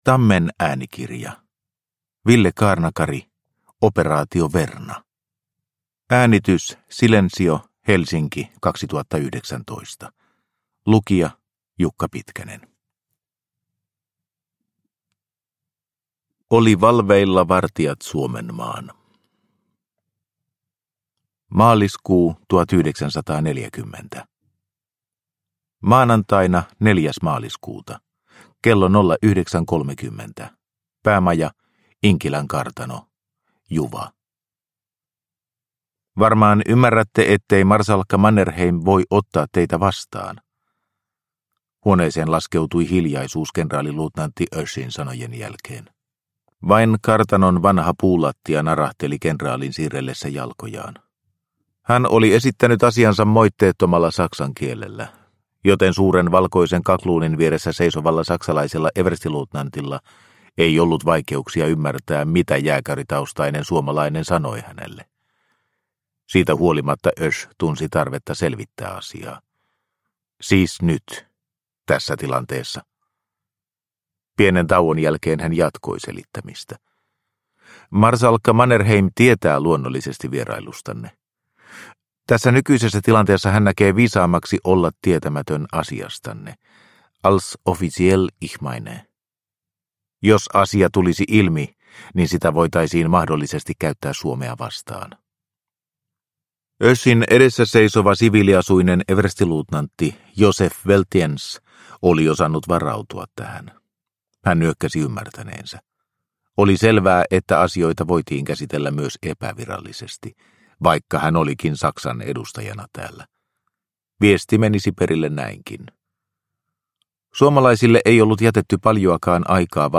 Operaatio Verna – Ljudbok – Laddas ner